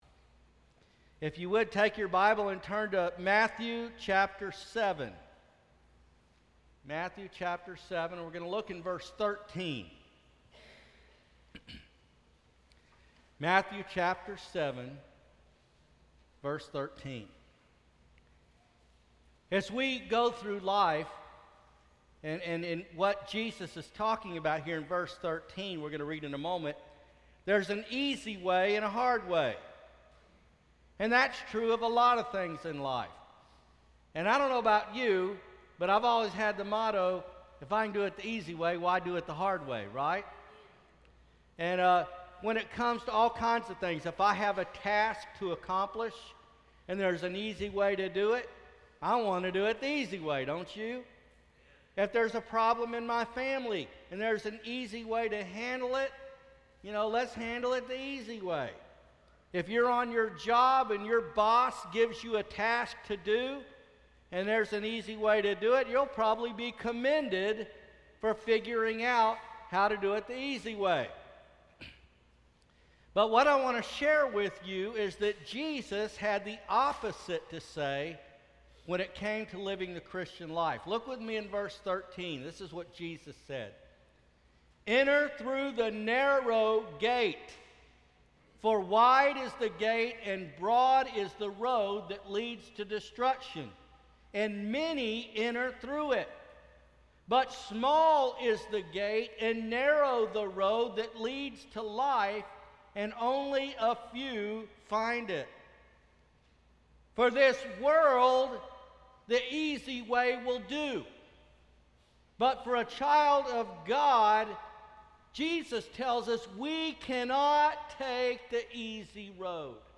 Passage: Matthew 7:1-13 Service Type: Sunday Morning Worship Bible Text: Matthew 7:1-13 God says there is a hard way and an easy way to live our lives.